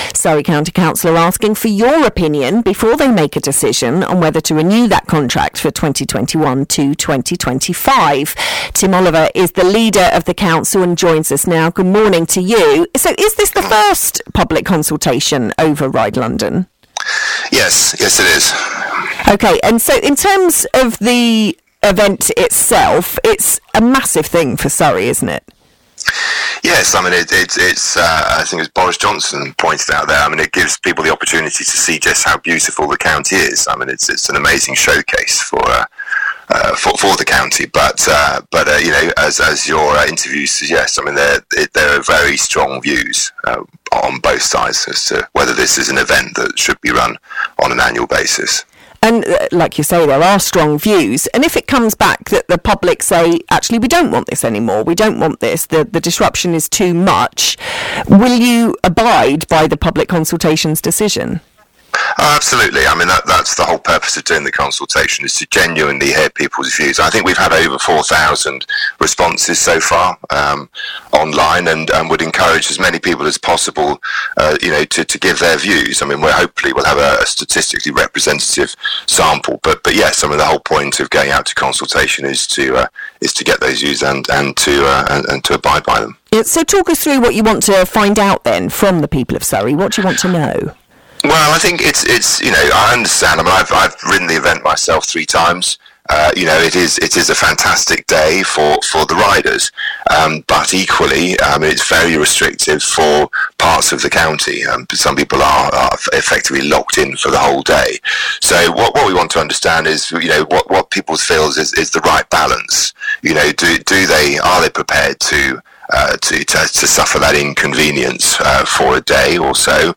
The Leader of Surrey County Council Tim Oliver talks to BBC Surrey about the survey on RideLondon.